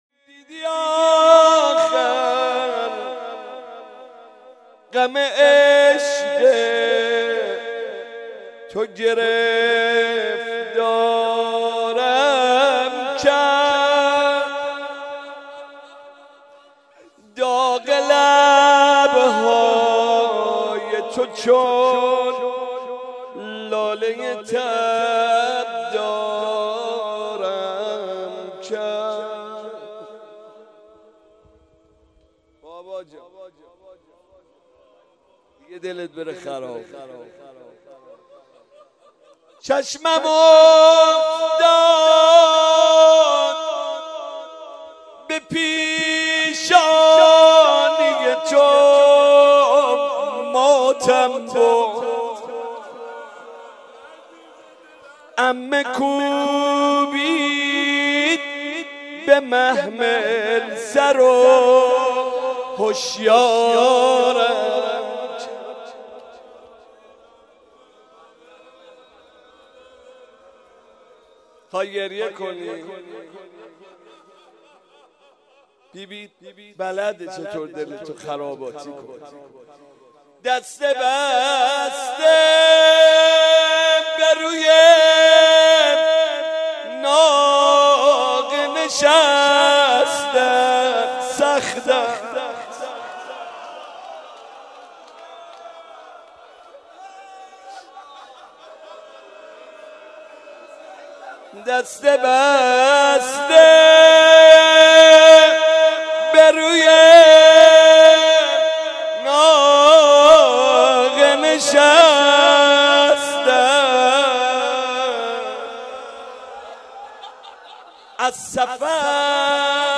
مداحی
مناجات
روضه